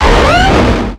Cri de Carchacrok dans Pokémon X et Y.